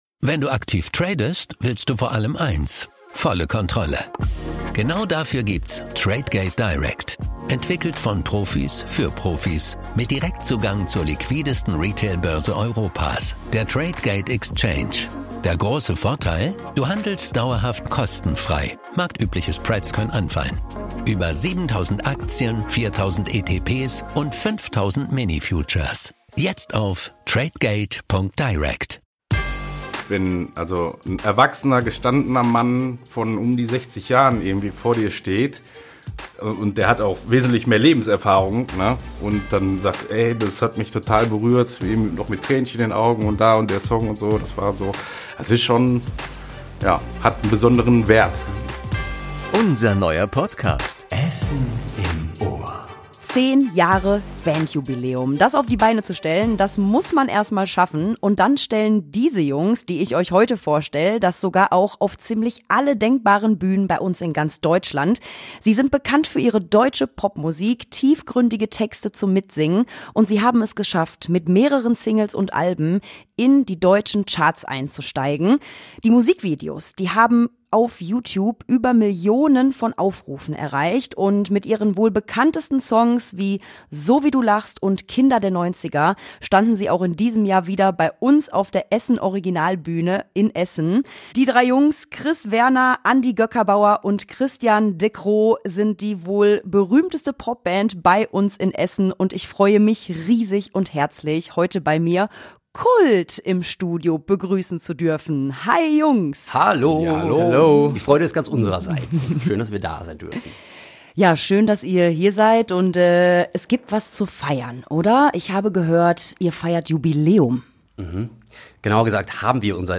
#110 KUULT: Die Essener Popband im Interview ~ Essen im Ohr - Der Talk mit Persönlichkeiten aus der Stadt Podcast